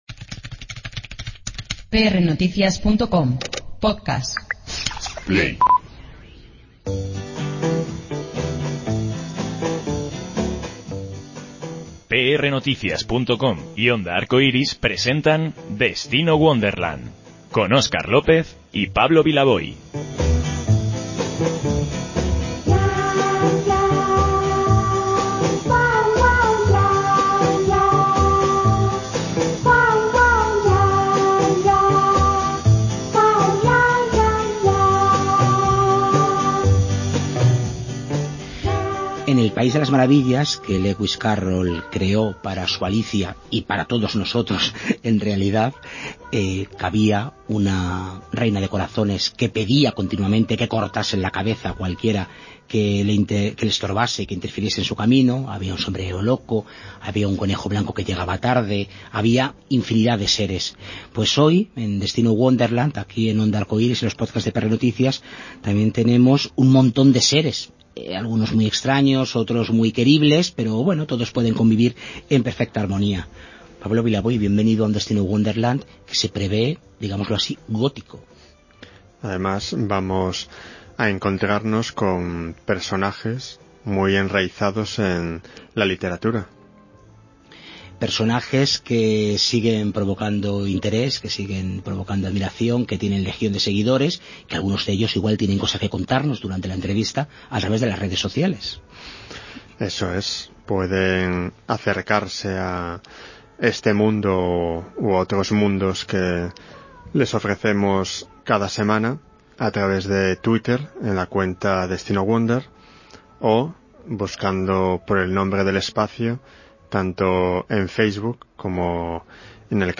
Conocemos detalles de su carrera, y de la ficción en esta entrevista.